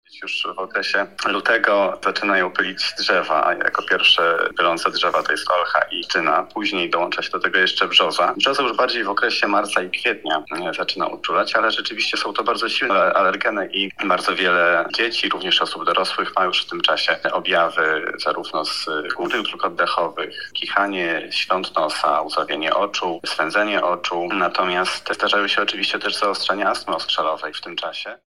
lekarz alergolog